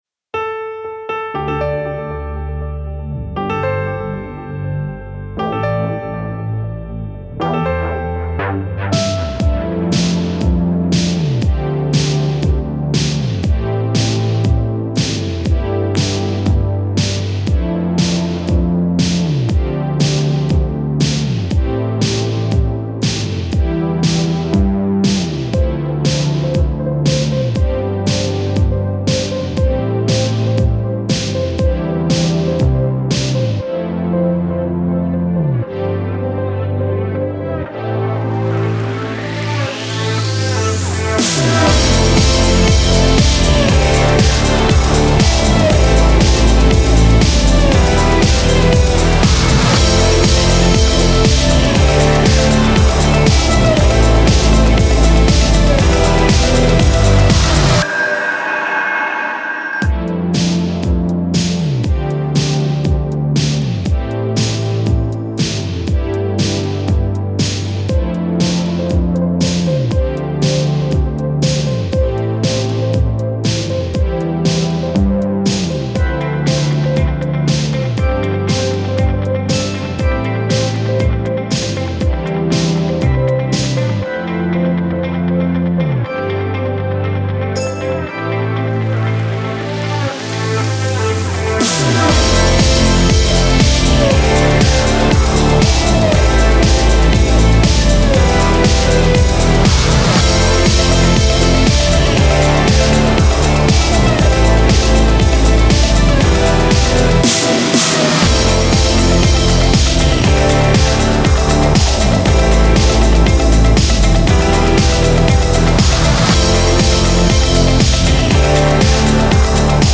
Last Time [Pop] (Sommerhit 2022 Contest Gewinnersong)
Im Vergleich zur Contest-version habe ich den Reverb/Delay-Anteil der Vocals reduziert und insgesamt die Vox etwas leiser gemacht. Songtempo 119 BPM
Vocals: Rode NT1A - getuned und bereits leicht vorkomprimiert